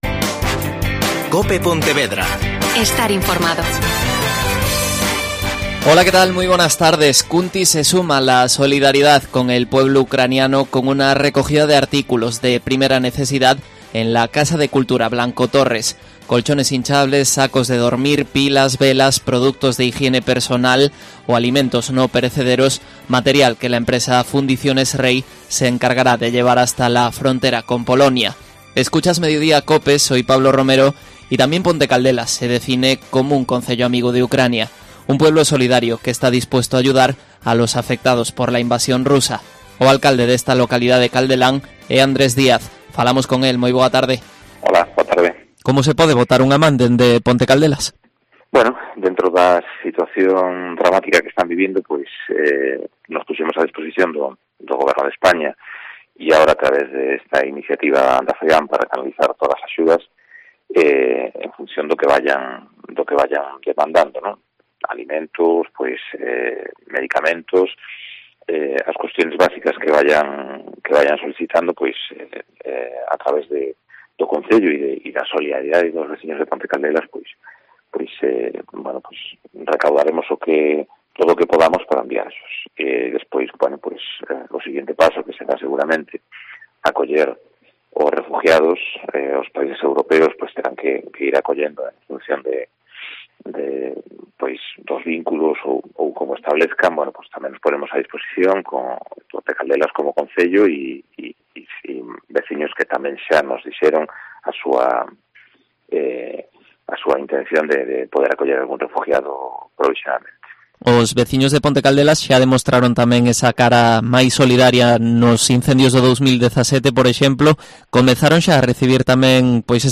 Entrevista a Andrés Díaz, alcalde de Ponte Caldelas